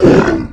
zombie_hit.ogg